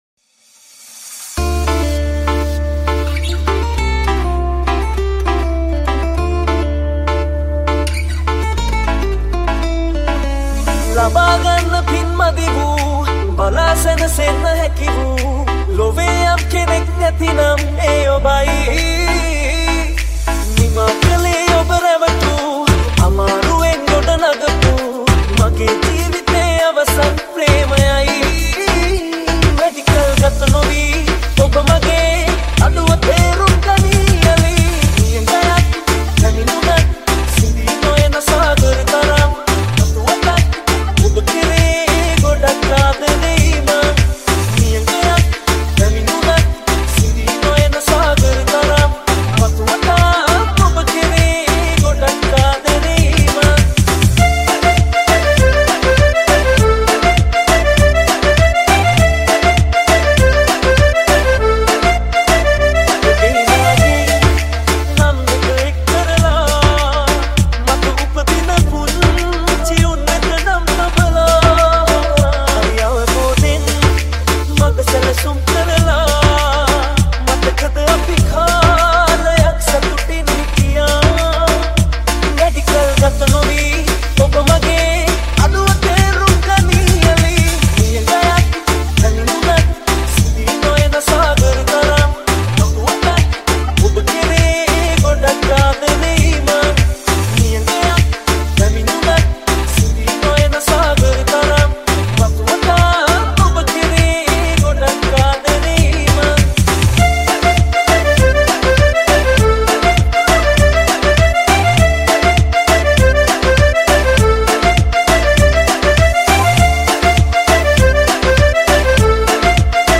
High quality Sri Lankan remix MP3 (3.3).